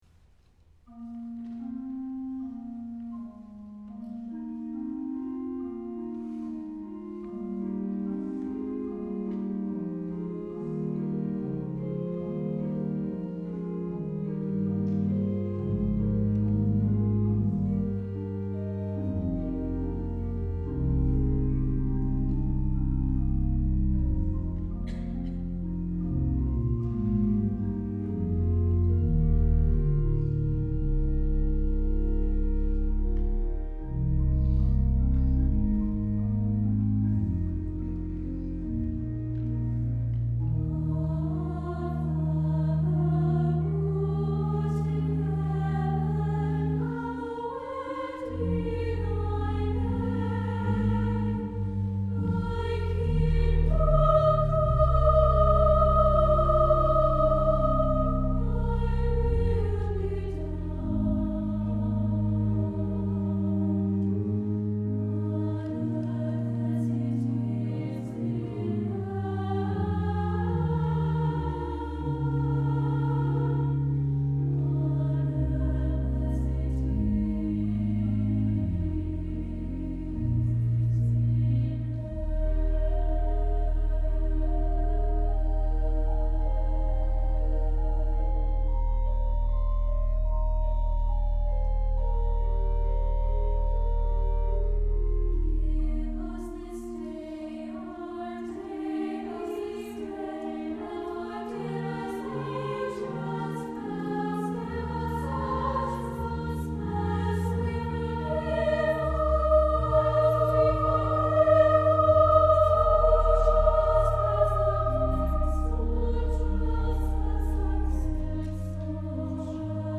for SSA Chorus and Organ (1988)
This return is the climax of the work, with the choir singing in a 3-part canon.
This is an extended (4-minute) and dramatic setting of The Lord's Prayer.